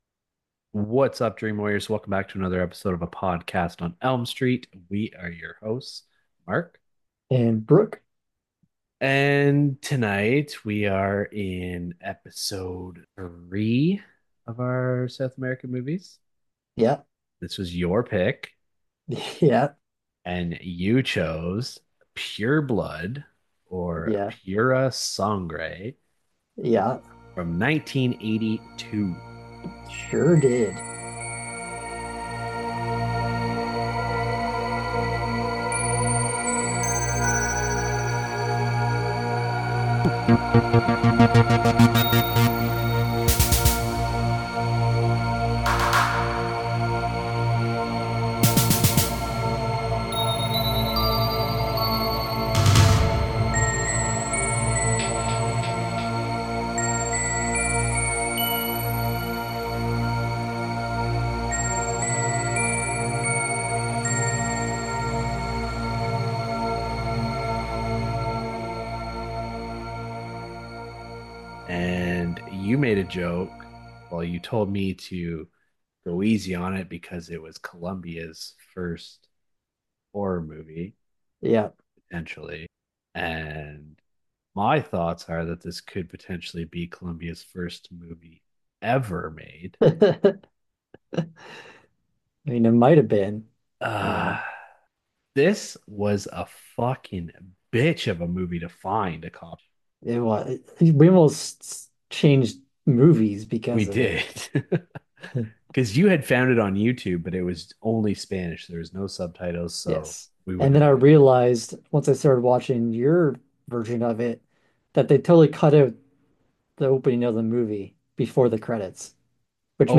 A podcast discussing horror movies from 2 Canadian horror movie fans. Every week we get together and discuss a different movie in length.